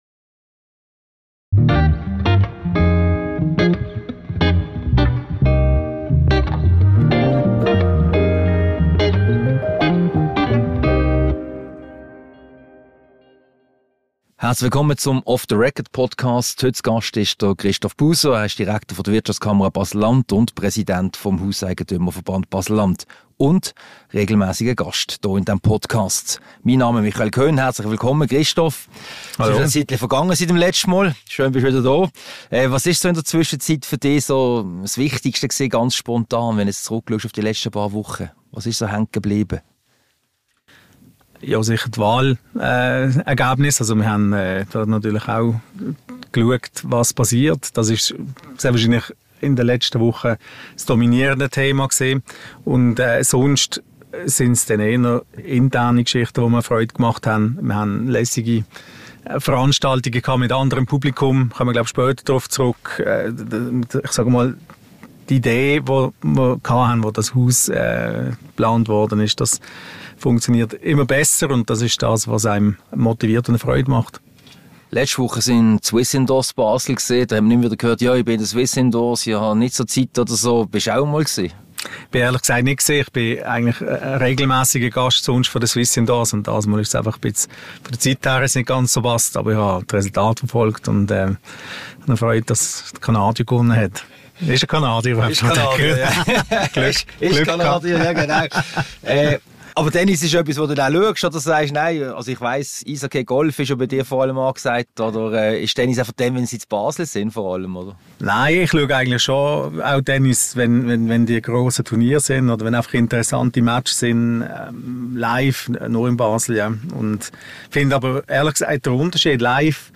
Ein Gespräch über die vergangenen nationalen Wahlen 2023, die kantonale Politik und Ausblicke auf den Tag der Wirtschaft und die Berufsschau.